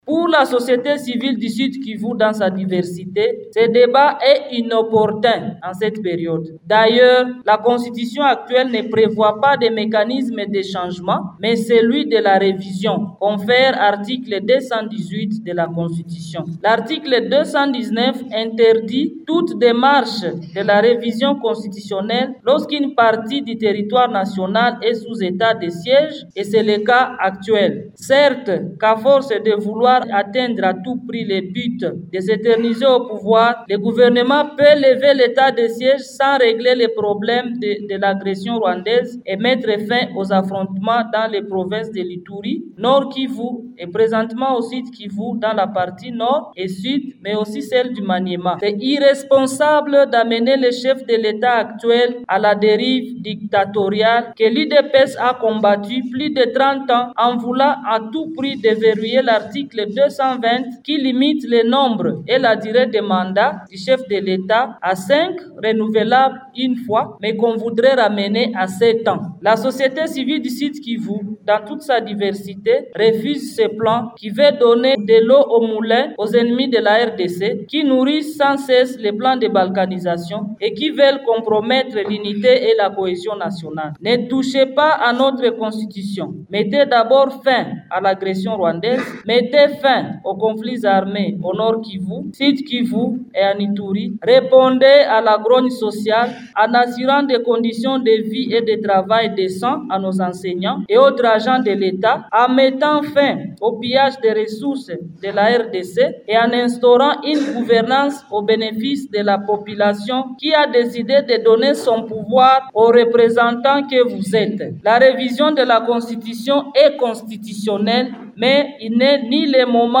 ELEMENT-DE-REPORTAGE-FR.mp3